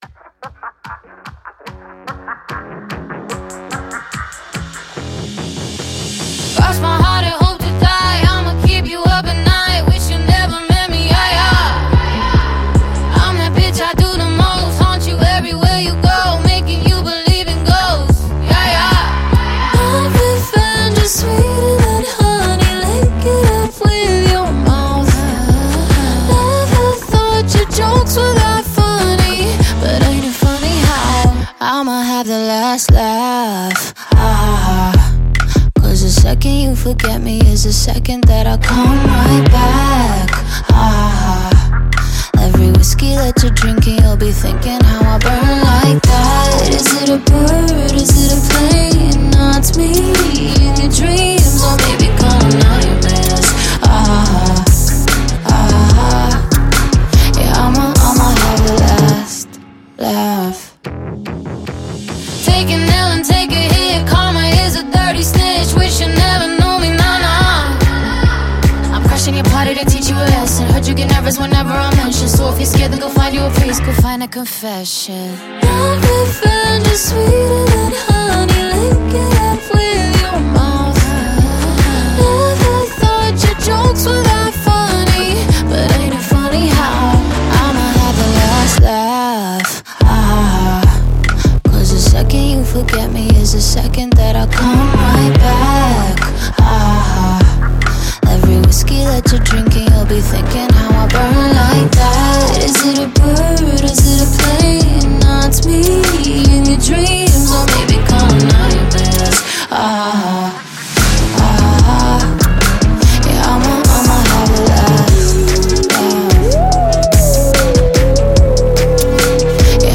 Жанр: Саундтреки / Саундтреки